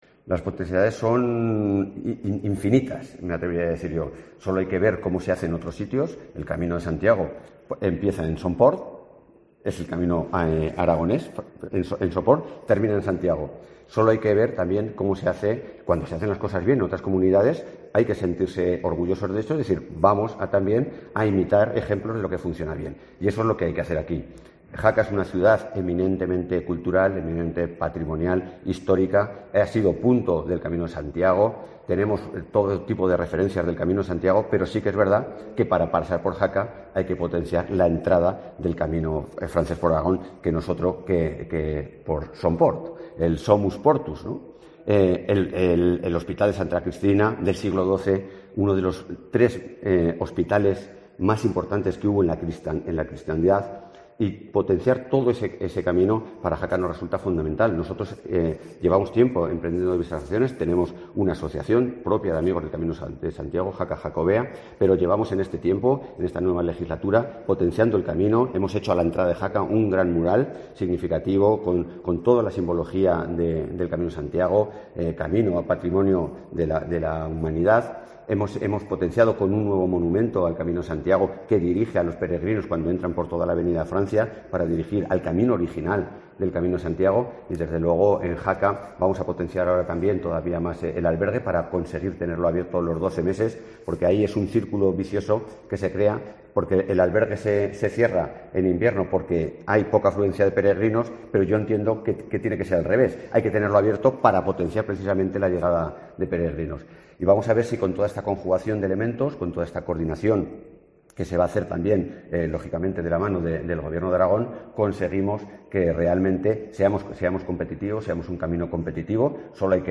Carlos Serrano, alcalde de Jaca